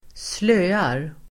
Uttal: [²sl'ö:ar]